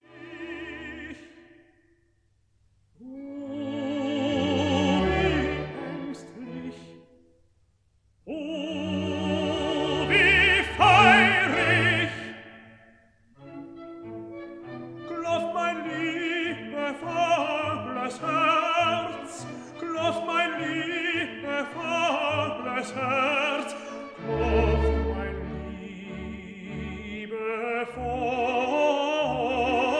Stereo recording made in Berlin June 1960